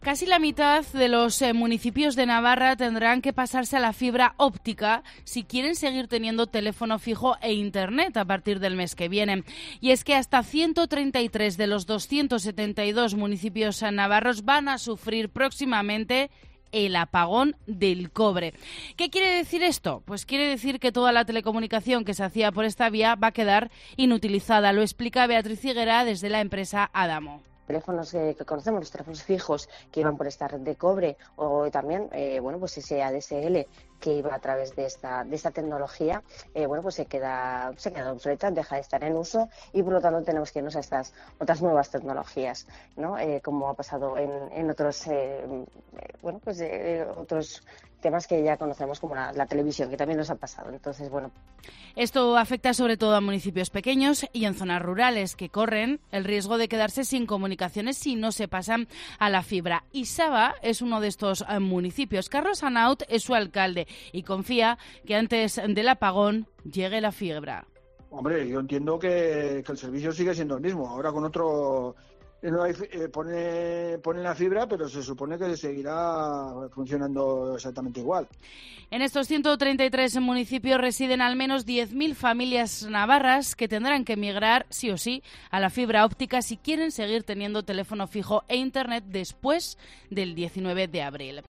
Noticia apagón